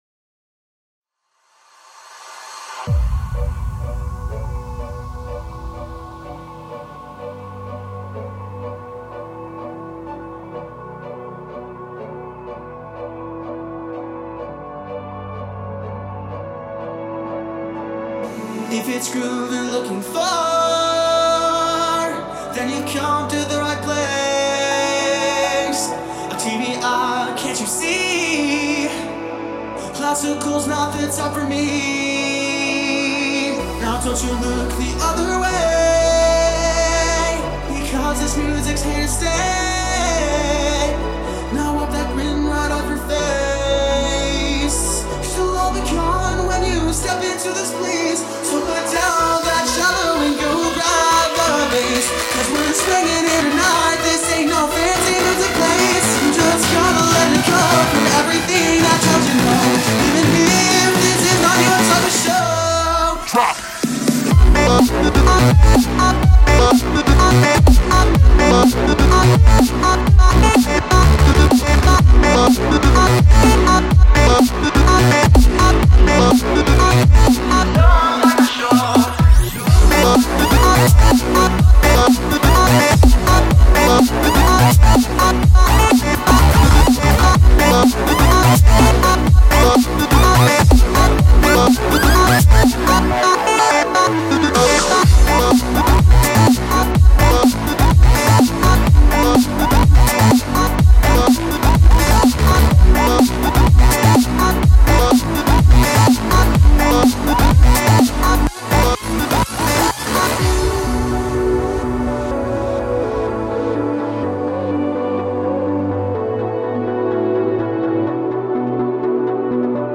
:) I just turned the swing hall into a breakbeat dancefloor!